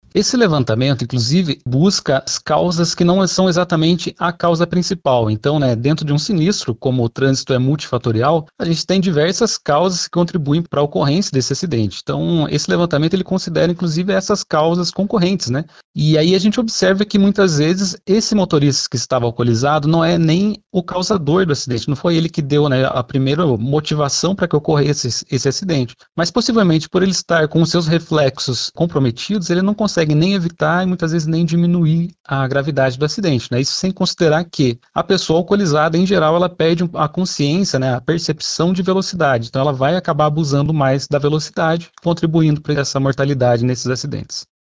SONORA-AUMENTO-MORTES-PRF-2-SP.mp3